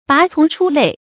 拔叢出類 注音： ㄅㄚˊ ㄘㄨㄙˊ ㄔㄨ ㄌㄟˋ 讀音讀法： 意思解釋： 猶言拔萃出類。指高出眾人。